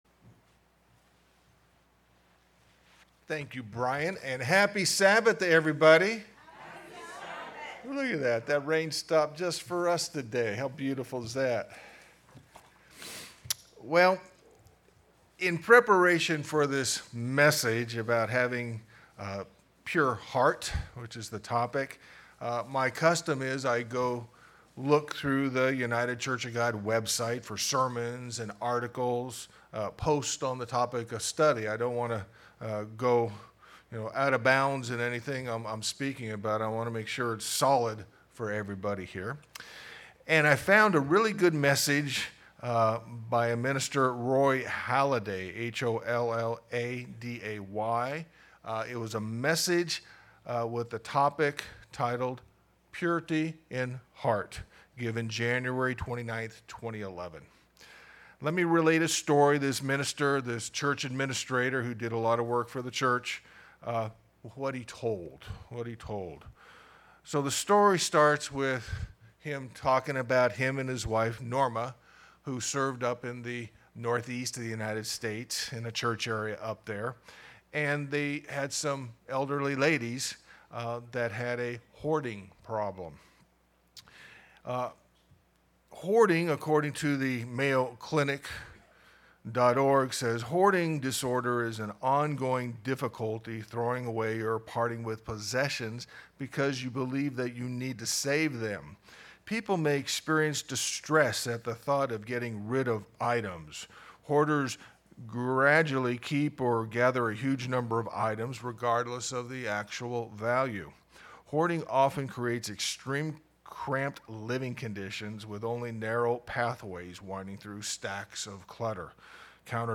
Sermons
Given in Orange County, CA